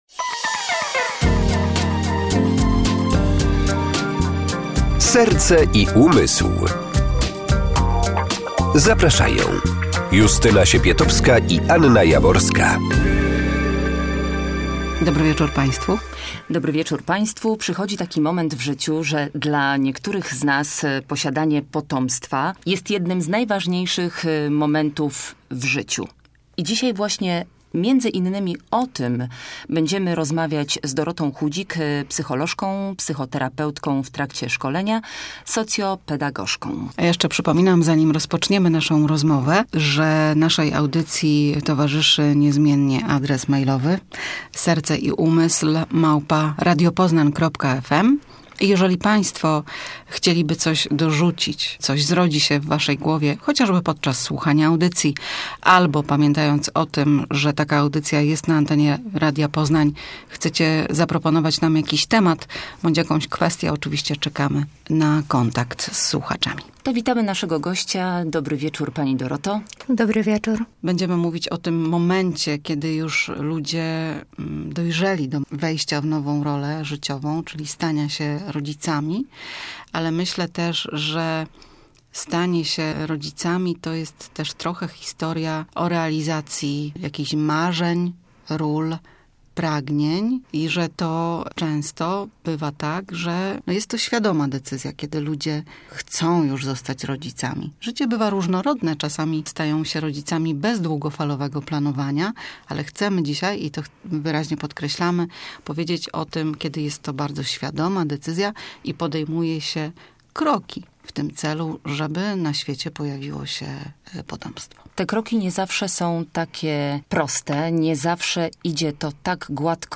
ROZMOWA O EMOCJACH, KTÓRE POJWIAJĄ SIĘ PRZY DŁUGOTRWAŁYCH STARANIACH O DZIECKO,